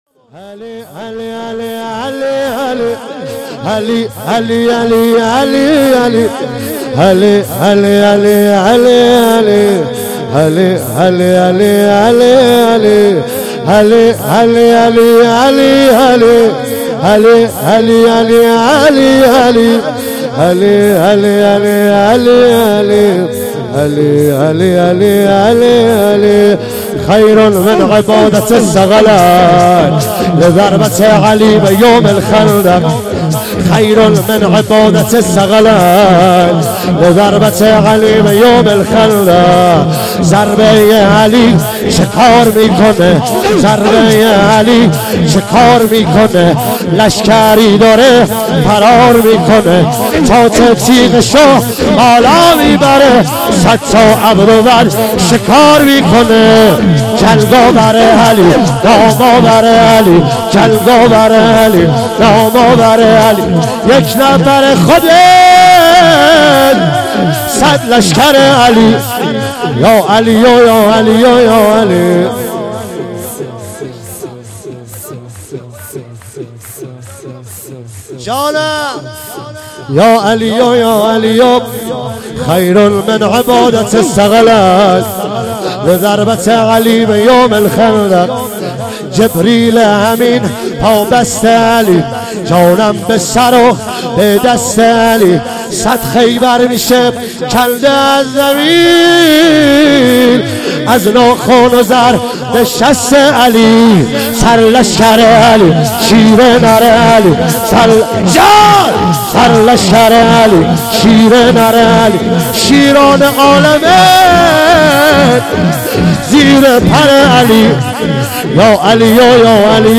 عنوان ولادت حضرت زهرا ۱۳۹۹ – شاندیز مشهد
سرود